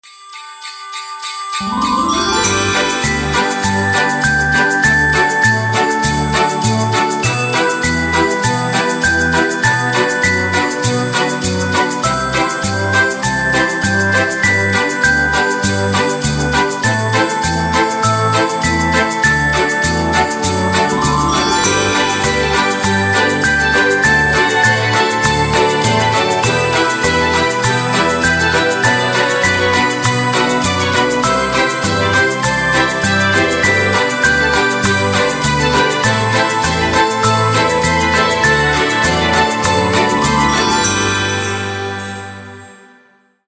без слов
инструментальные
добрые
колокольчики
рождественские